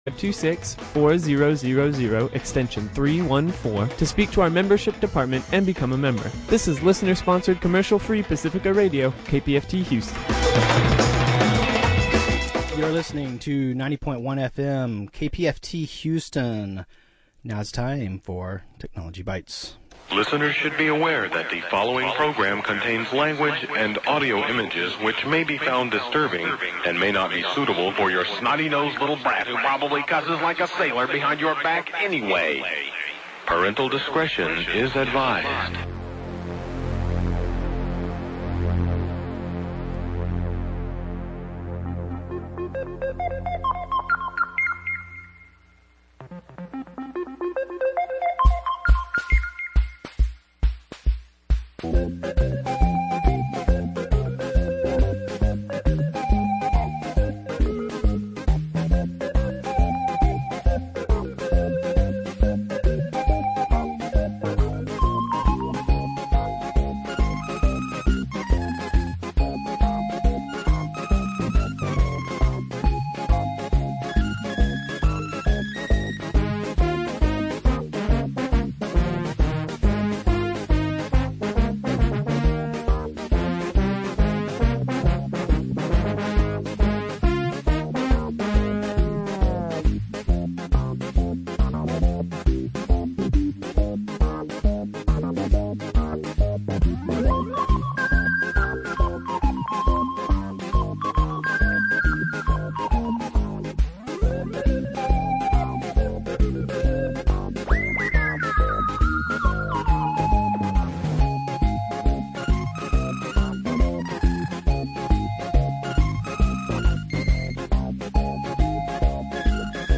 New format including your calls!